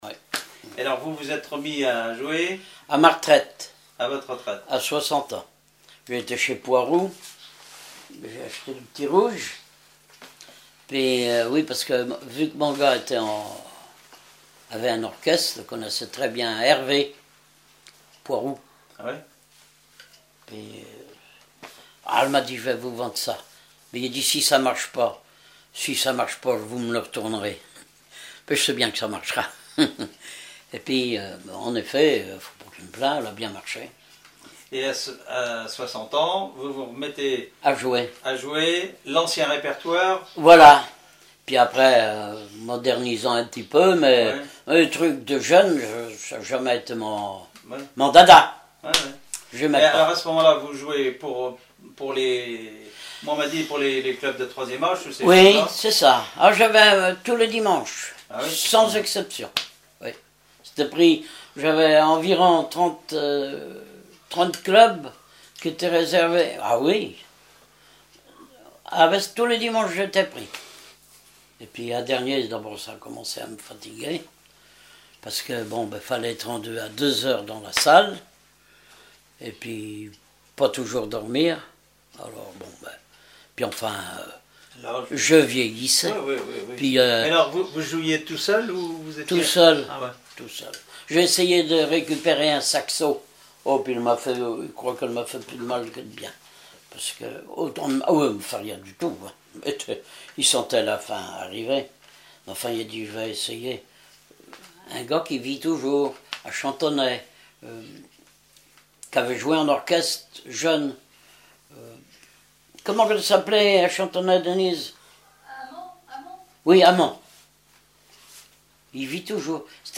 Enquête Compagnons d'EthnoDoc - Arexcpo en Vendée
Catégorie Témoignage